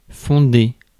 Ääntäminen
IPA : /ˈfɑʊnd/